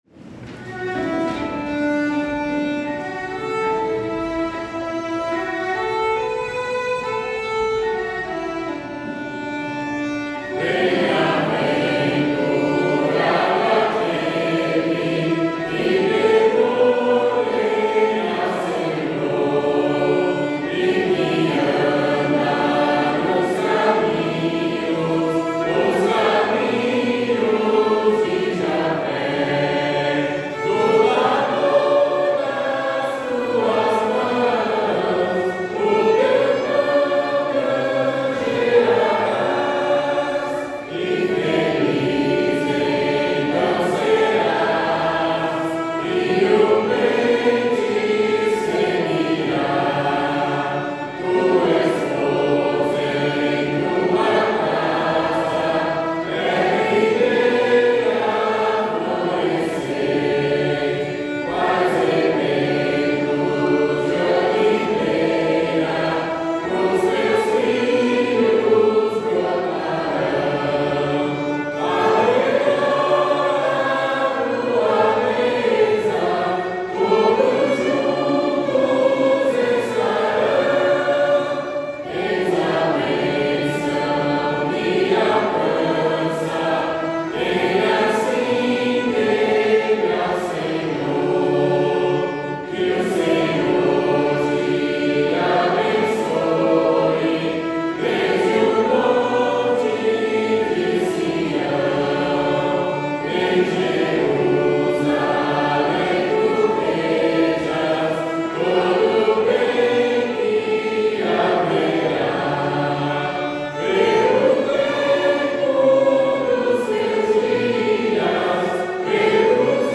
Métrica: 8. 7. 8. 7
Melodia tradicional americana
salmo_128B_cantado.mp3